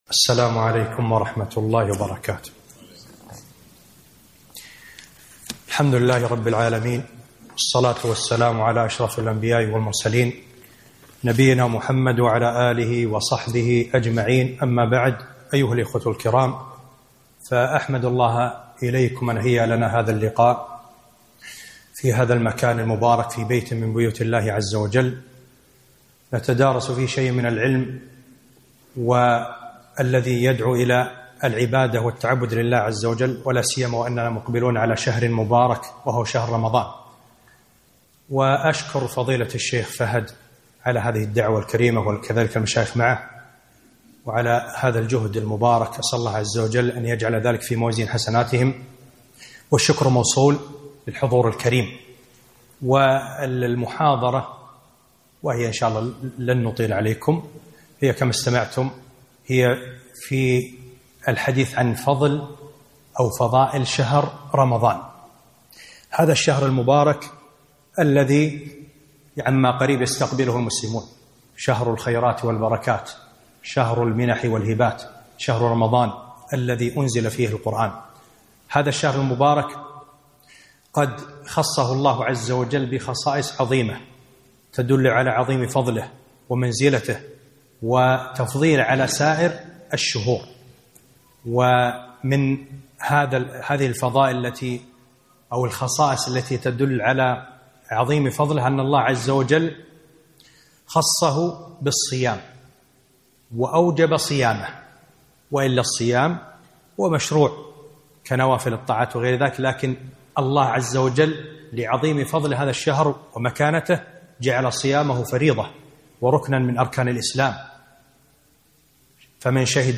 محاضرة - فضل شهر رمضان